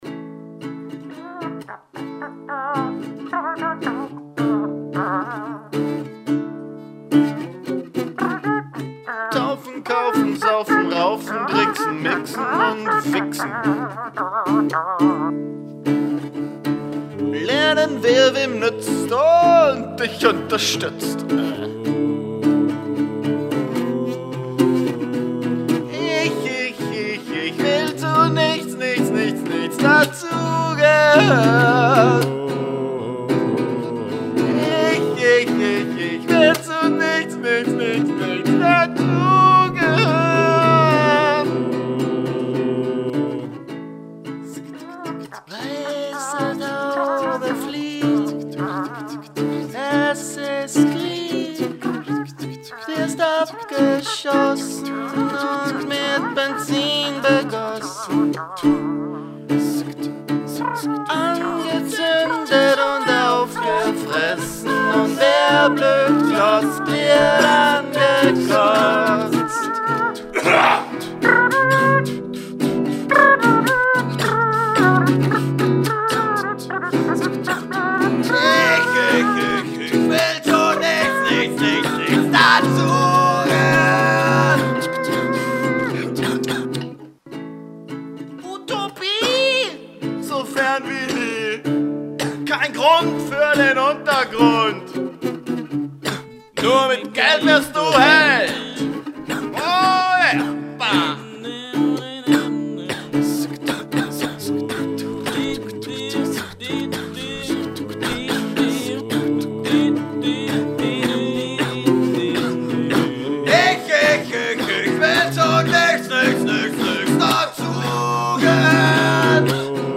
Rau und brutal und toll-wütig.